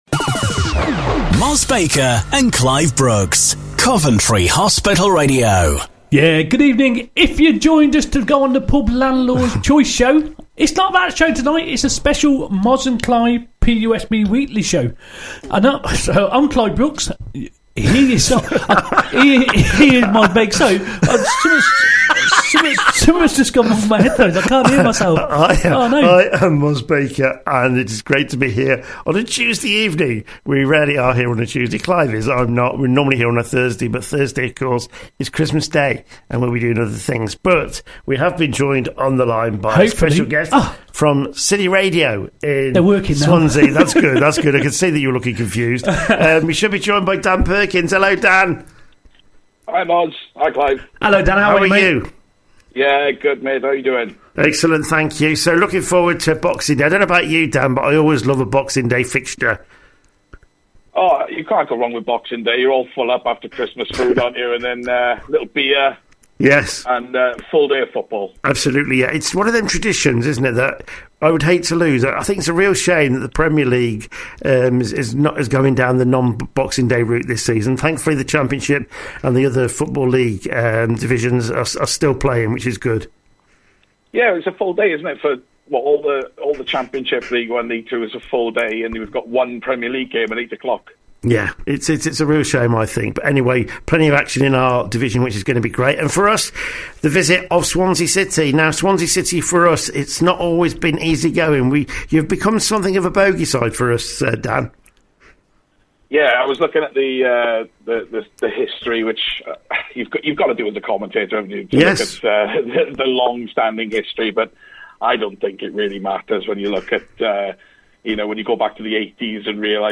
Coventry Hospital Radio Interview.mp3